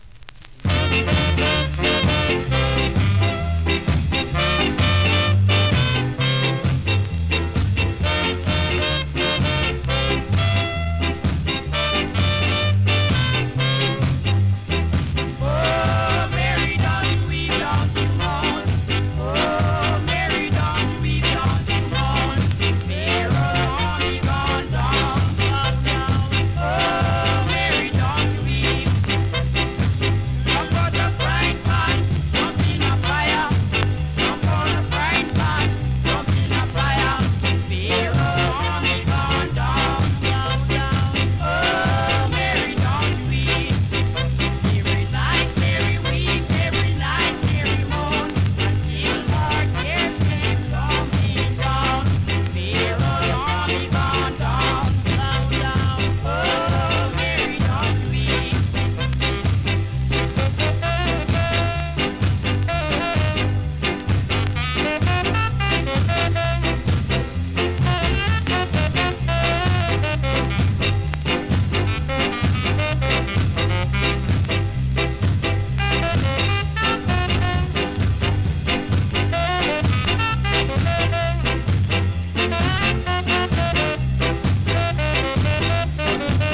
SKA!!
スリキズ、ノイズそこそこありますが